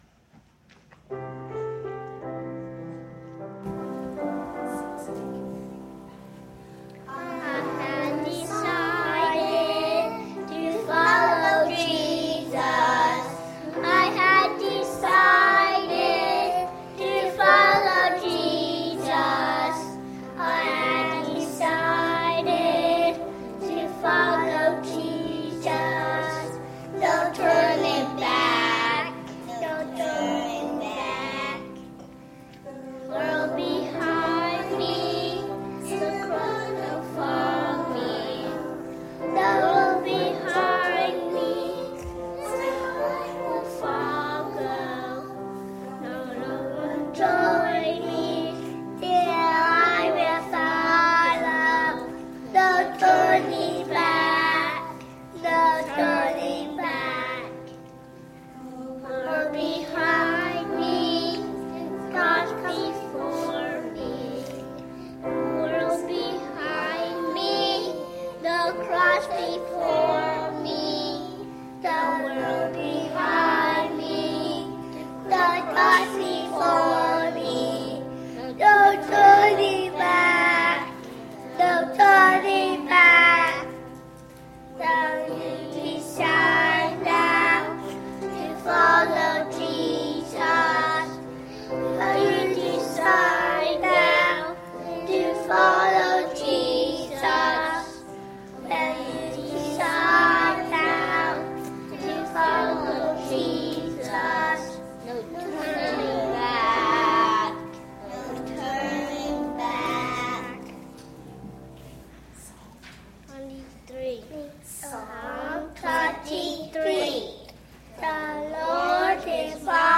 2012 Childrens’ Christmas Program
Sunday, December 23, 2012 – Childrens’ Christmas Program